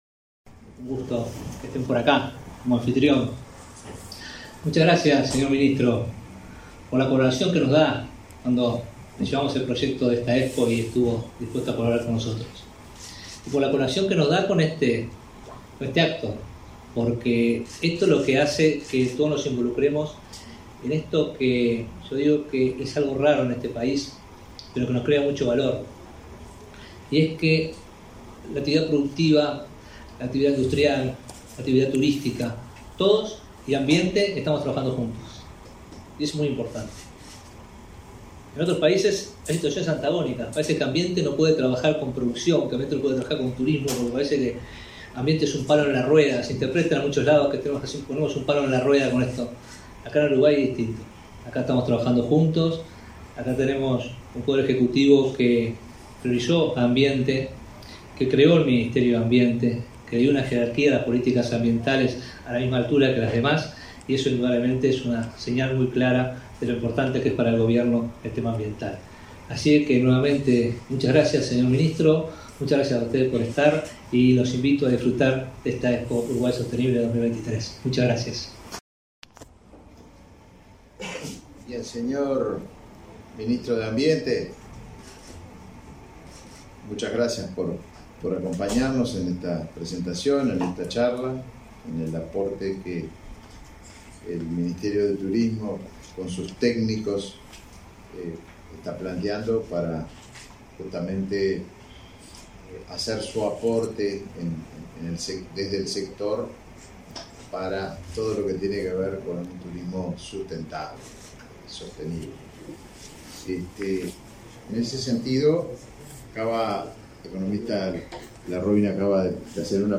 Palabras de los ministros de Turismo, Tabaré Viera, y de Ambiente, Robert Bouvier
Los ministerios de Turismo y de Ambiente presentaron, este 9 de junio, medidas para la promoción del turismo, en el marco de la Expo Sostenible 2023.